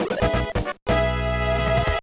Self game-rip
Fair use music sample